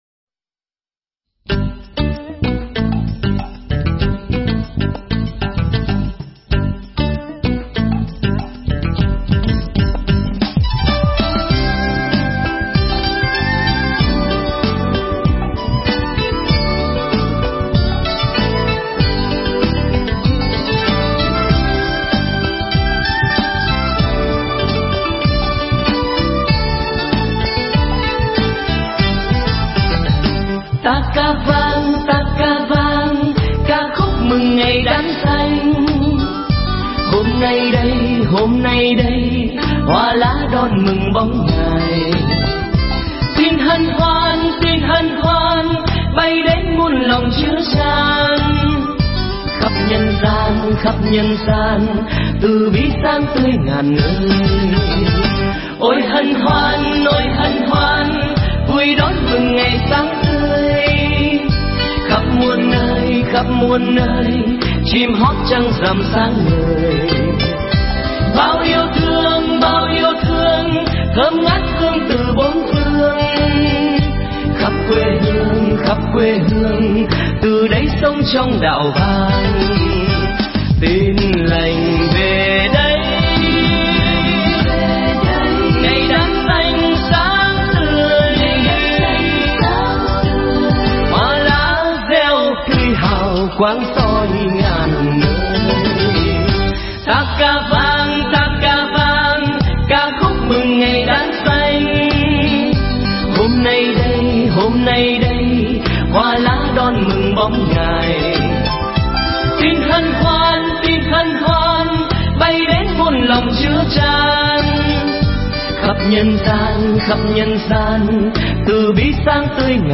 thuyết giảng tại Chùa Phổ Minh